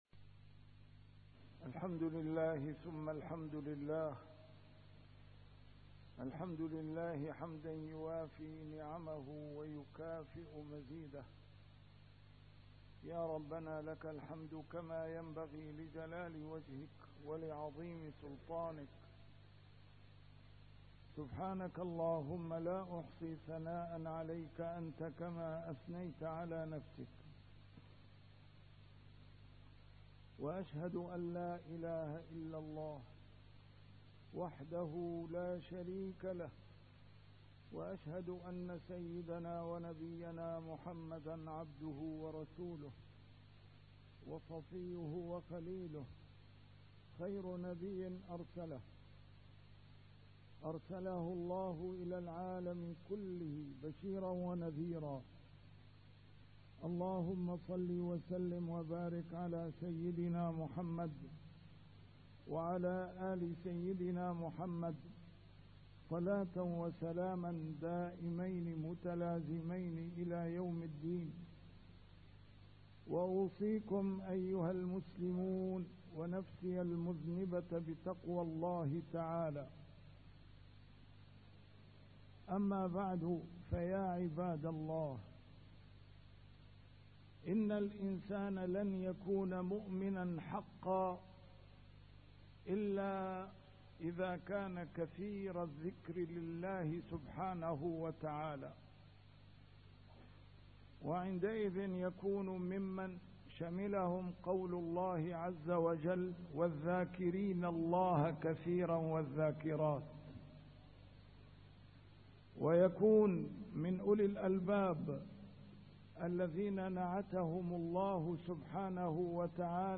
A MARTYR SCHOLAR: IMAM MUHAMMAD SAEED RAMADAN AL-BOUTI - الخطب - معاني تحملها فصول العام.. ينبغي إدراكها